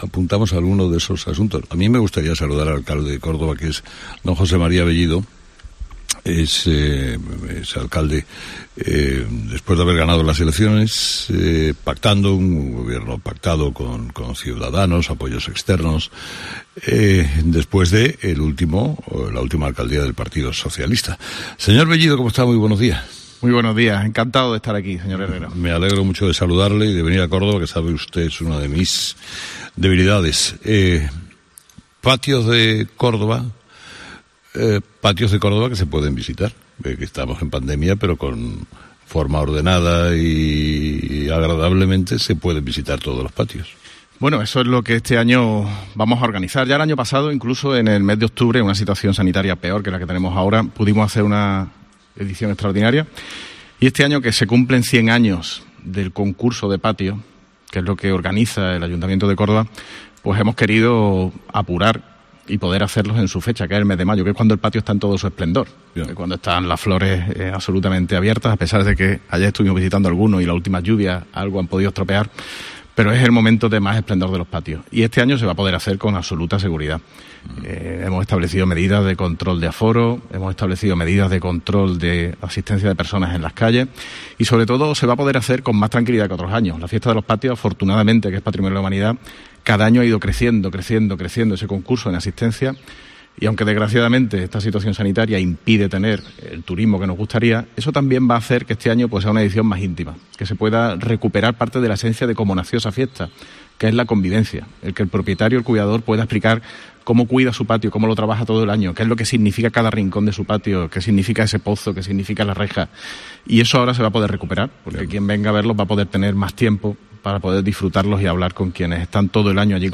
El alcalde de Córdoba ha explicado a Carlos Herrera cómo la ciudad está preparada para recibir al turismo de una forma segura